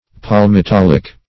Search Result for " palmitolic" : The Collaborative International Dictionary of English v.0.48: Palmitolic \Pal`mi*tol"ic\, a. [Palmitic + -oleic + ic.]
palmitolic.mp3